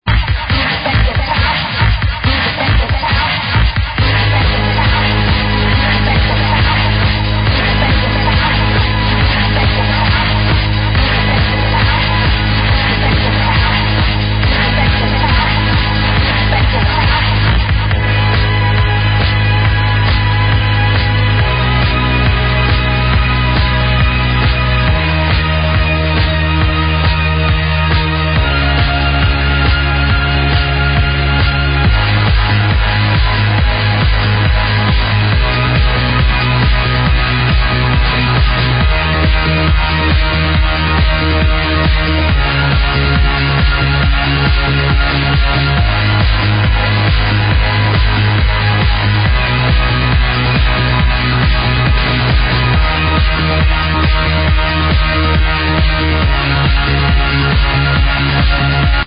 the melody / structure of the bassline